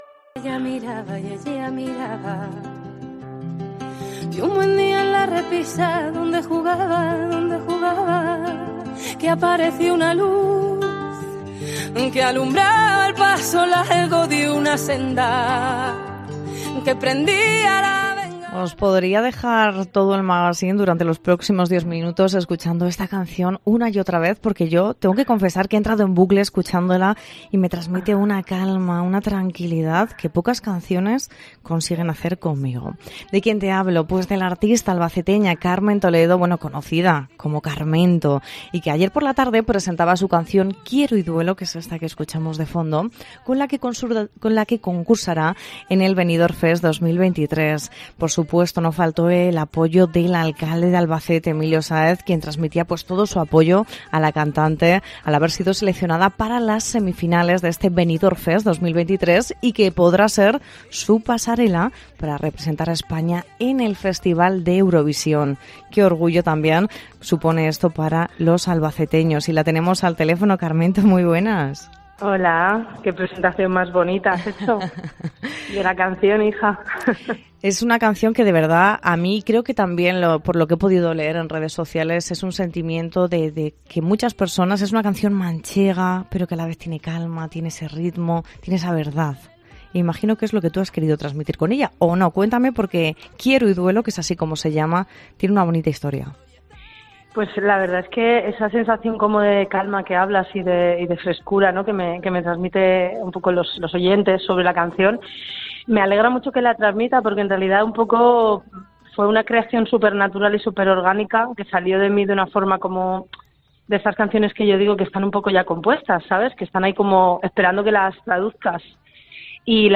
Entrevista con la cantante albaceteña